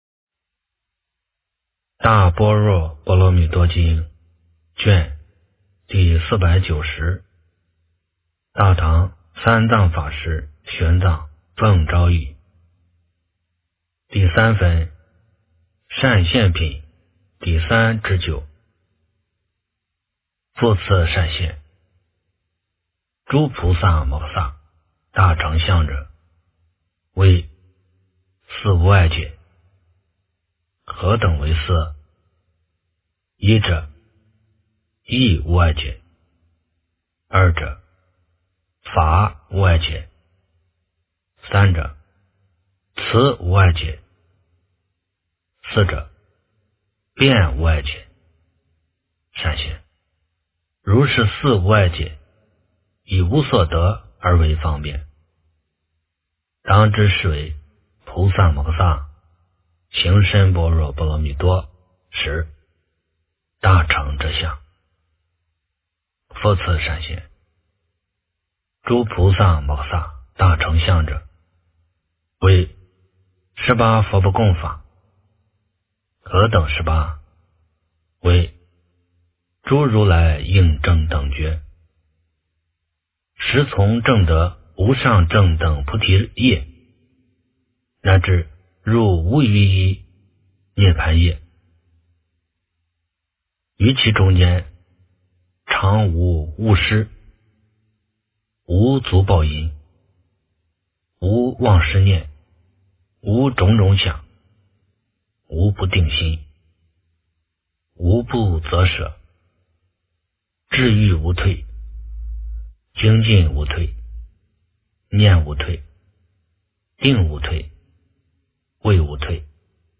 大般若波罗蜜多经第490卷 - 诵经 - 云佛论坛